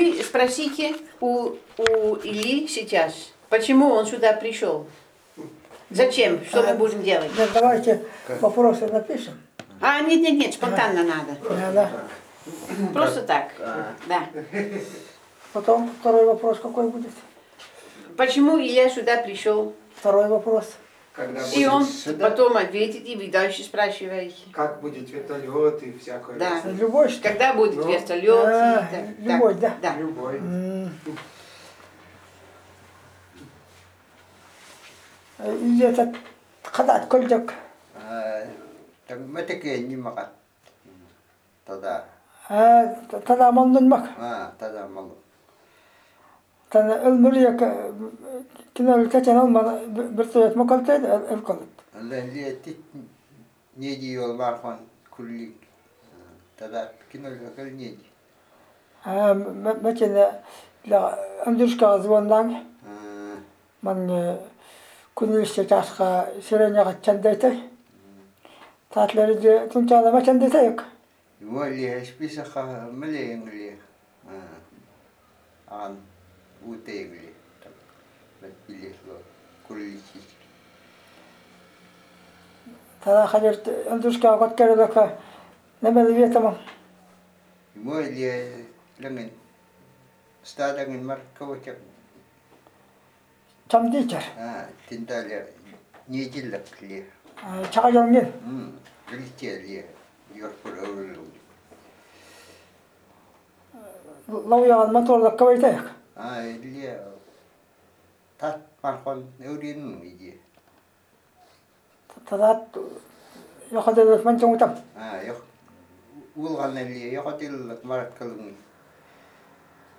Conversation (TY0022) – Endangered Languages and Cultures of Siberia